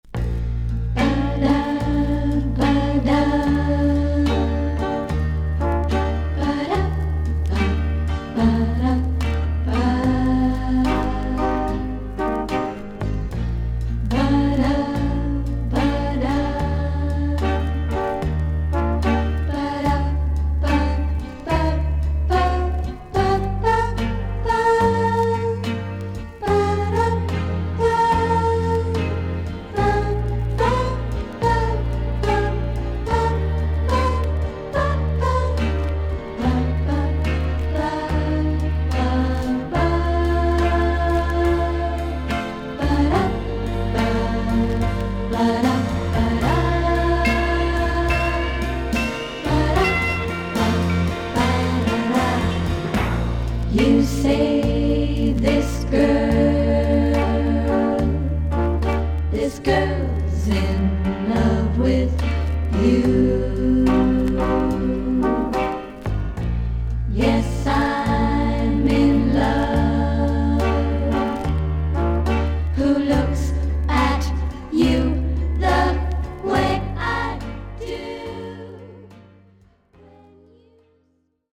甘くドリーミーなコーラス・ワークのソフトロック/ガールポップス名盤。
VG++〜VG+ 少々軽いパチノイズの箇所あり。クリアな音です。